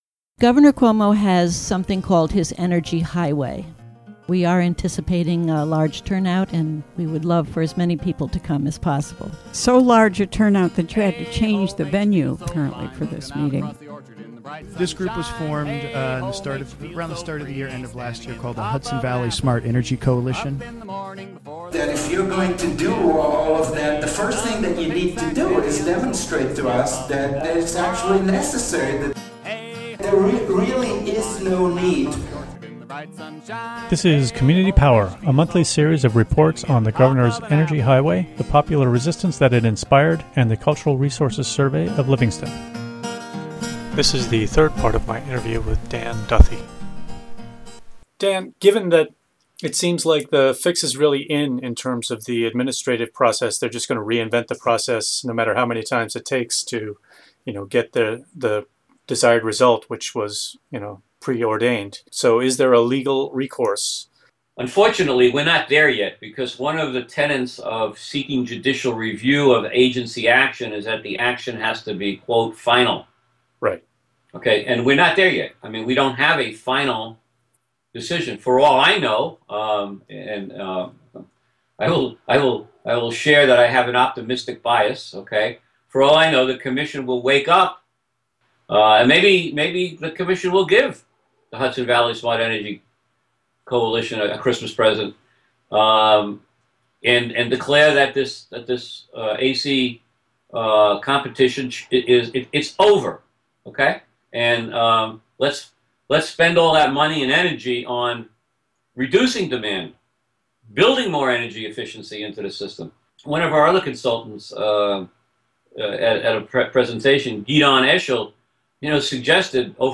WGXC Evening News